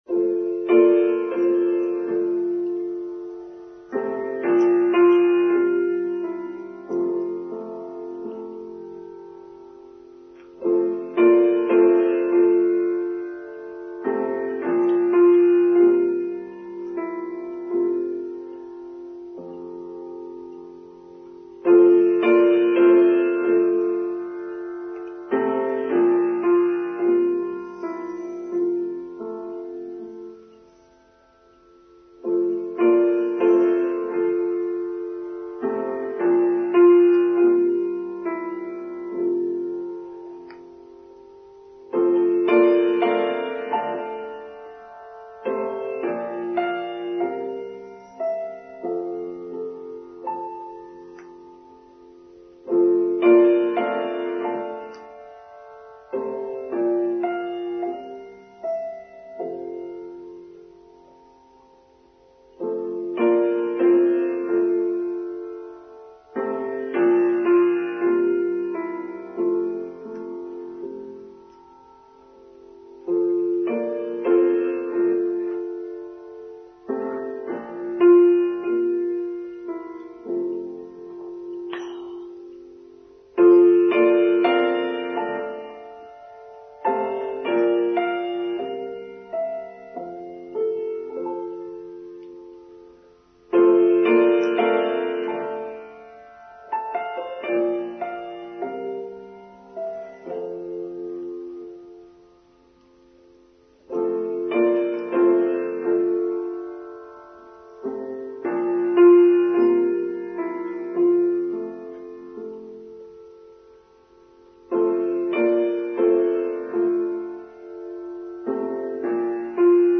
The Two Spirits of Christmas: Online Service for 25th December 2022